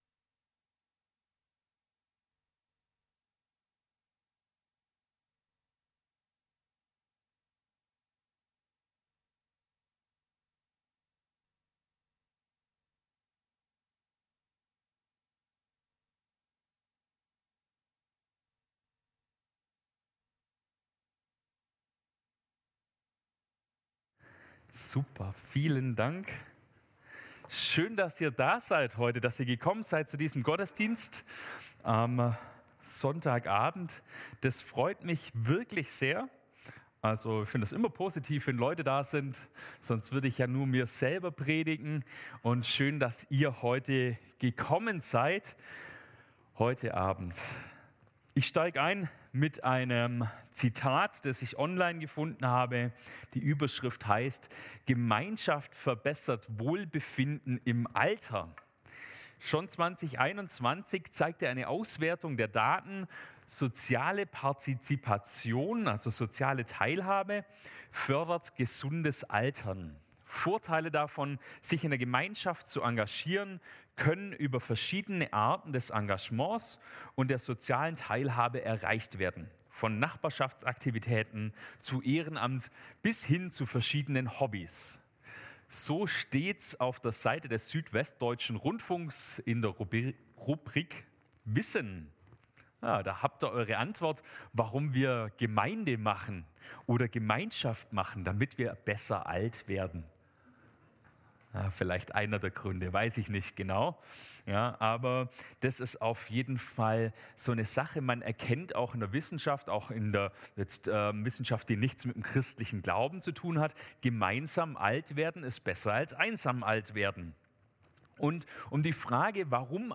Predigt am 03.03.2024